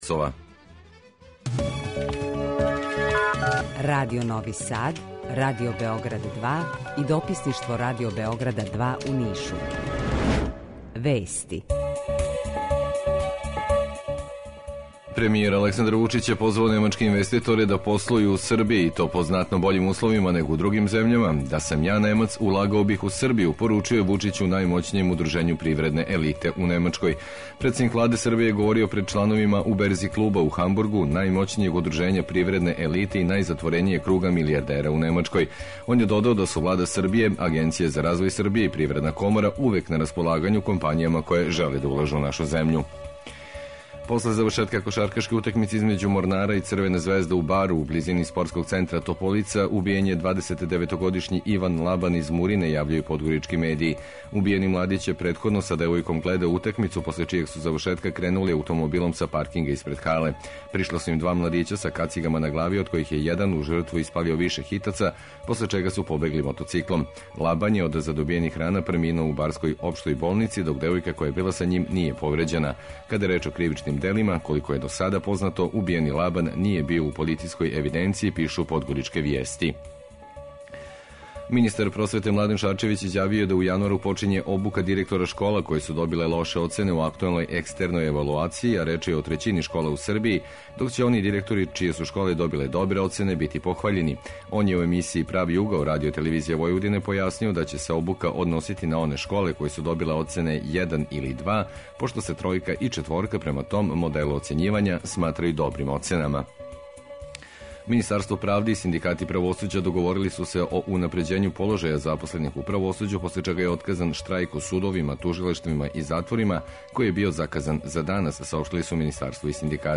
Jутарњи програм заједнички реализују Радио Београд 2, Радио Нови Сад и дописништво Радио Београда из Ниша.
У два сата биће и добре музике, другачије у односу на остале радио-станице.